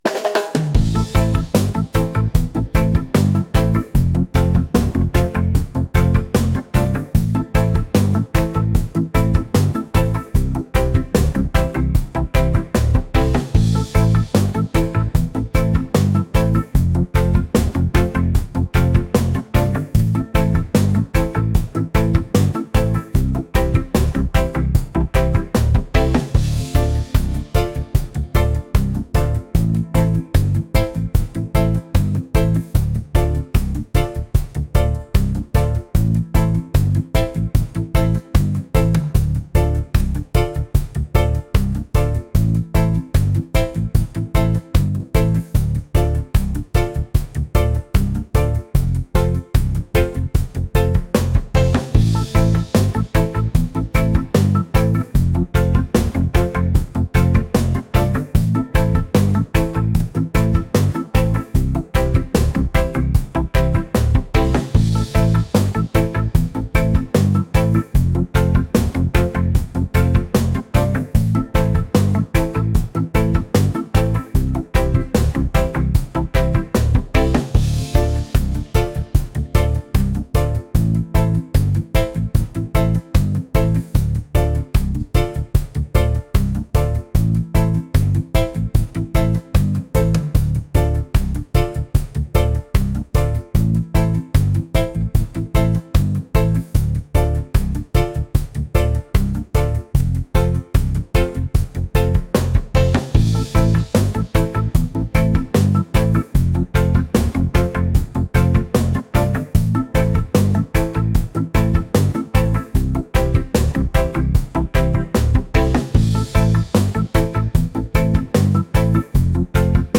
upbeat | reggae | fusion | rhythmic